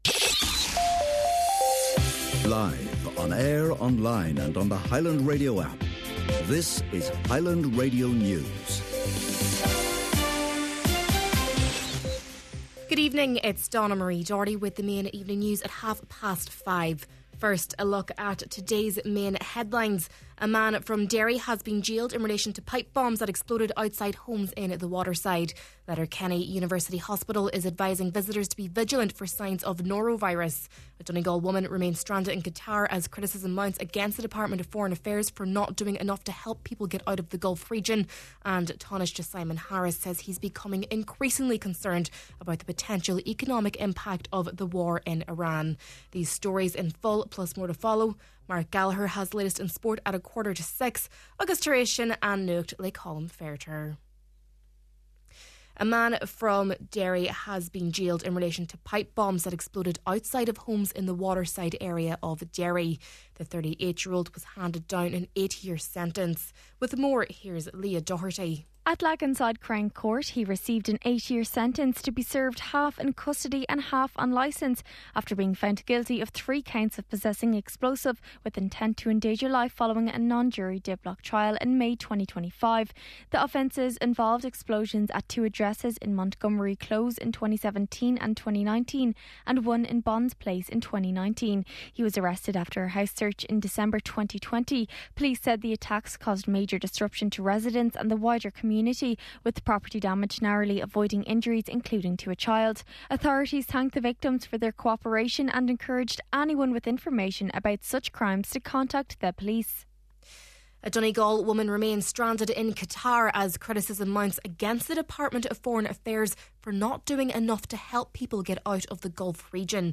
Main Evening News, Sport, An Nuacht and Obituary Notices – Friday, March 13th